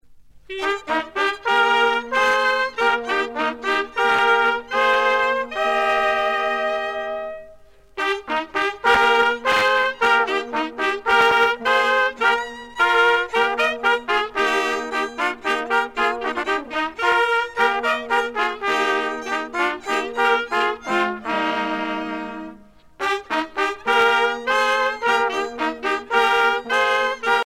Genre laisse
groupe folklorique
Pièce musicale éditée